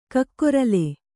♪ kakkorale